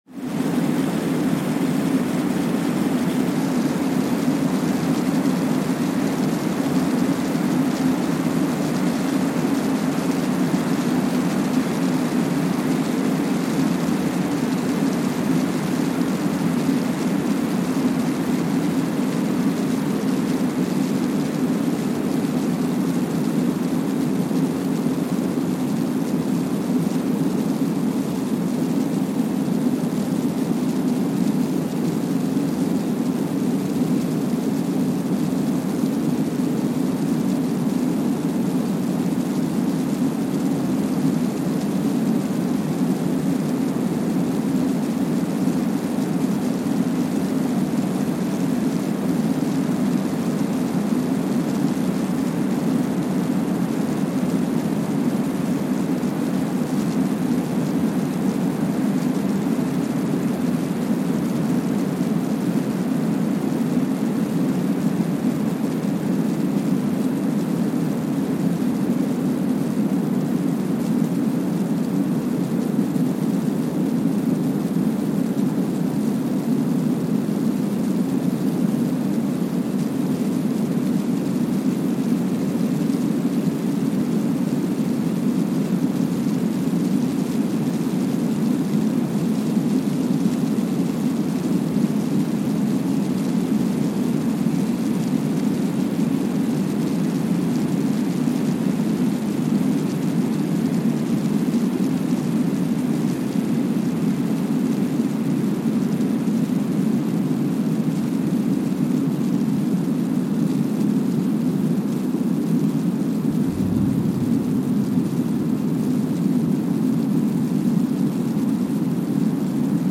Sensor : Streckeisen STS-5A Seismometer
Speedup : ×1,000 (transposed up about 10 octaves)
Gain correction : 25dB
SoX post-processing : highpass -2 90 highpass -2 90